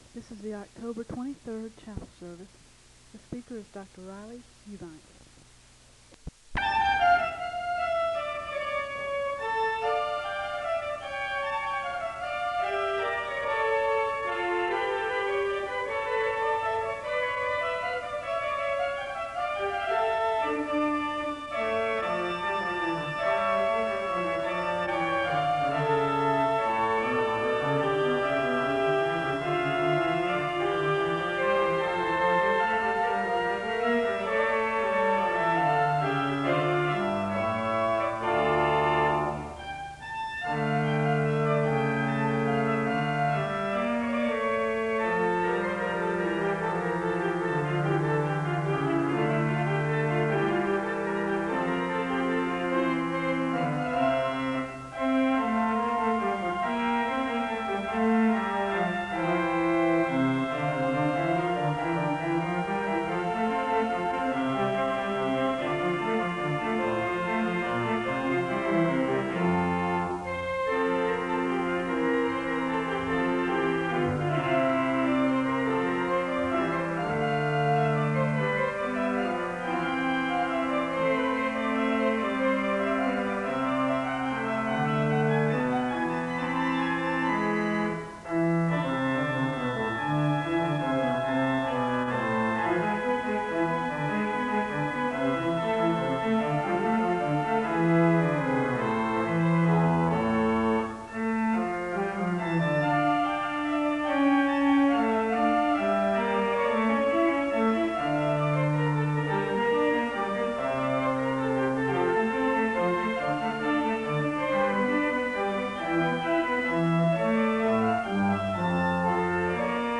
The service begins with organ music (00:00-05:42).